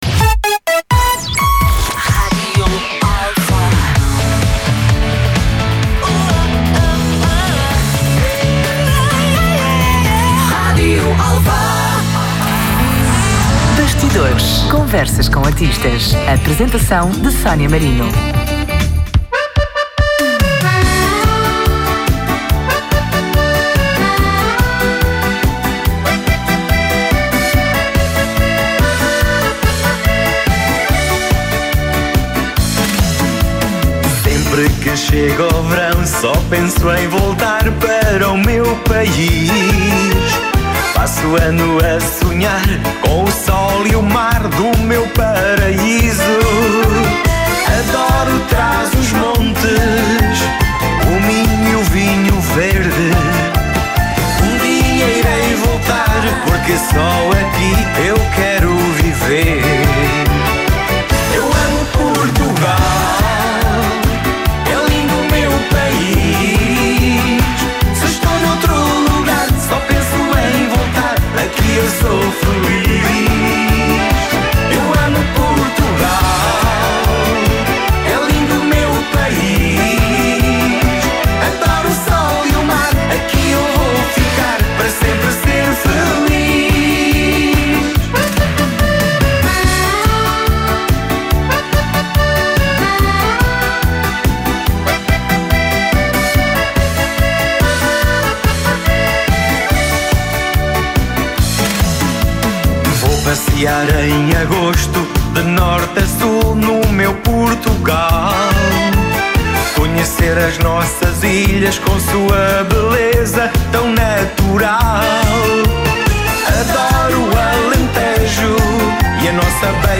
«Bastidores » Conversas com artistas na Rádio Alfa Apresentação